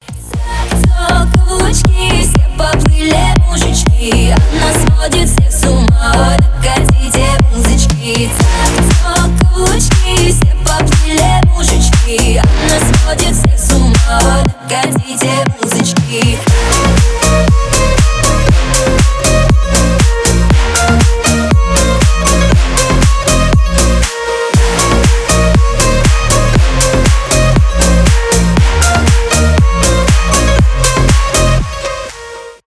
танцевальные
зажигательные